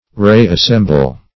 Re-assemble \Re`-as*sem"ble\(r[=e]`[a^]s*s[e^]m"b'l)
re-assemble.mp3